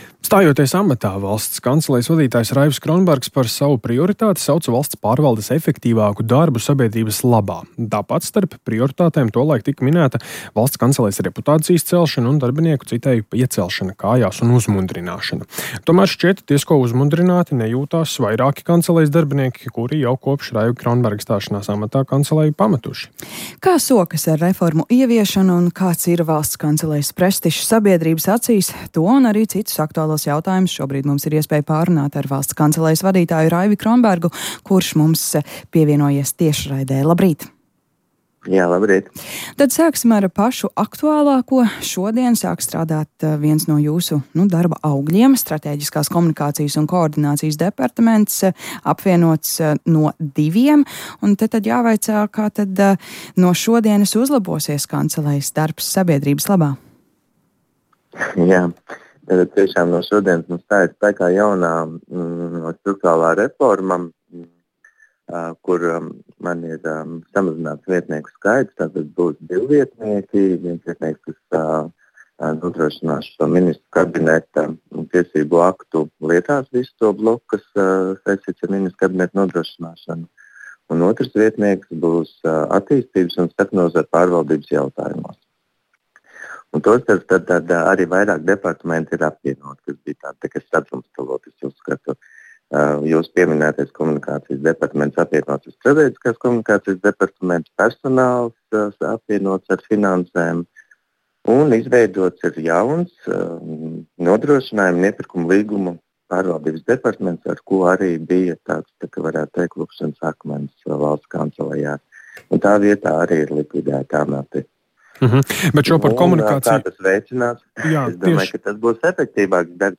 Kā intervijā Latvijas Radio raidījumā Labrīt sacīja Kronbergs, kancelejā tiek ieviestas plašākas pārmaiņas - tiek samazināts viņa vietnieku skaits līdz diviem, apvienoti arī citi departamenti, piemēram, personāla un finanšu departamenti.